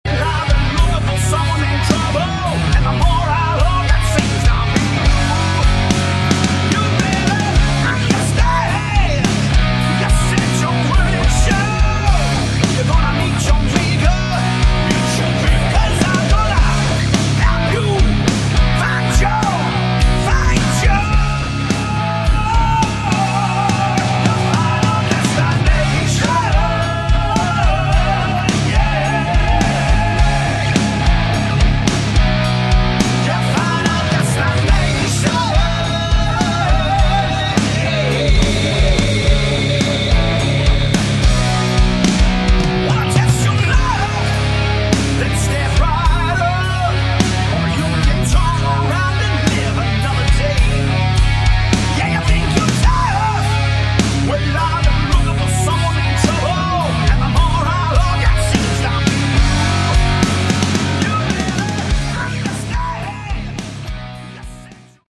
Category: Hard Rock
Lead Vocals
Lead Guitar, Vocals
Drums
Bass Guitar
Good hard rock with an excellent sound.